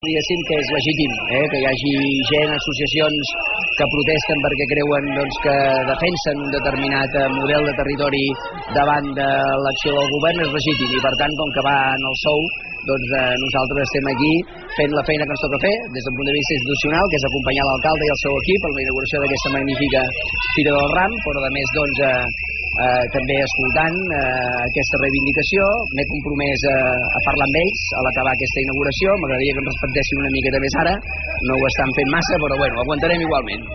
Damià Calvet, durant l’entrevista a Ona Maresme durant la FIra Mercat del Ram de Tordera.
Calvet, que anava acompanyat de diverses autoritats a nivell local, tant de Tordera com de municipis propers, ha passat per l’estand del programa Ona Maresme, on ha declarat que les protestes envers aquest projecte “són legítimes”, i que el seu paper a la fira responia al caràcter institucional d’acompanyar a l’alcalde de Tordera, Joan Carles Garcia.